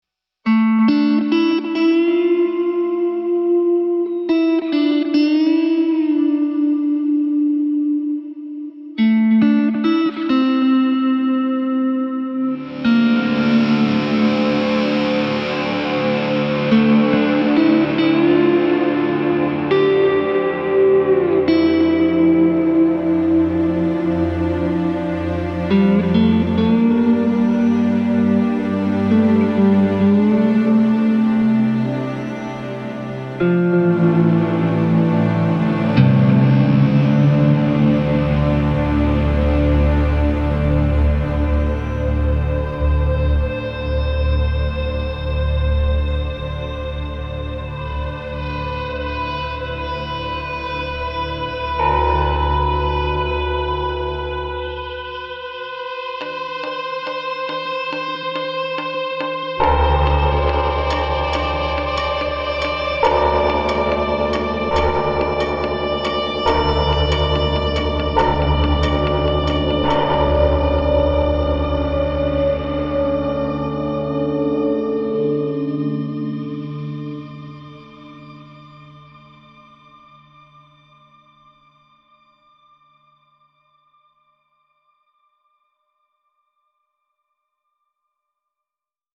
Thriller
Tension